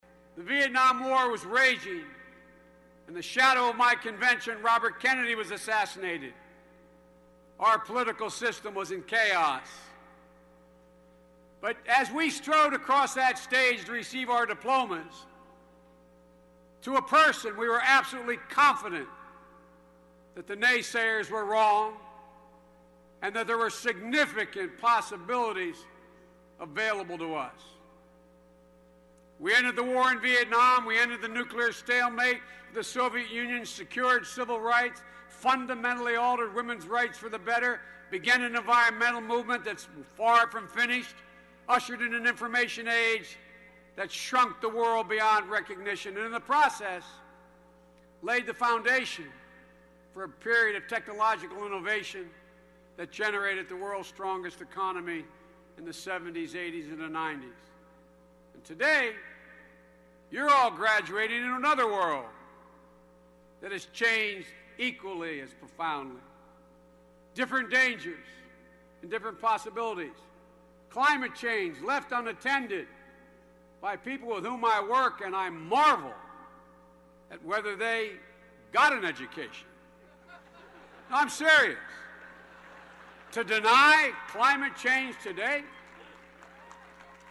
公众人物毕业演讲第408期:拜登2013宾夕法尼亚大学(6) 听力文件下载—在线英语听力室